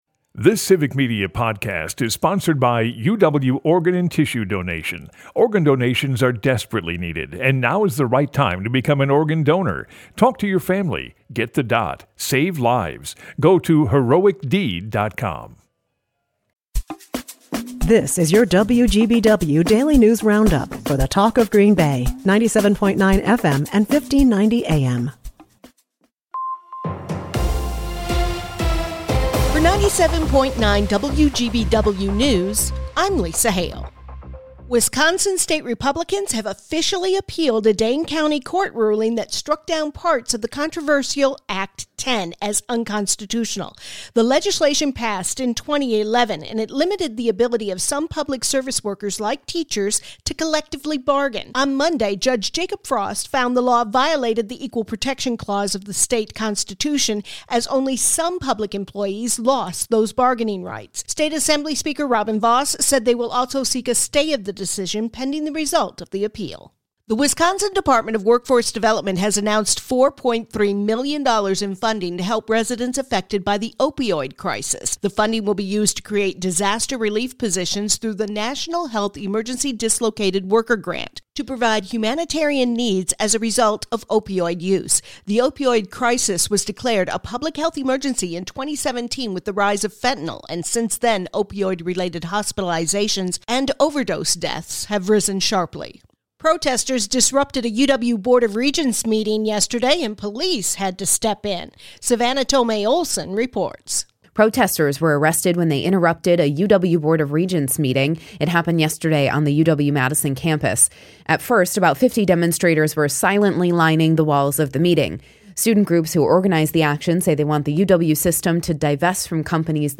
wgbw news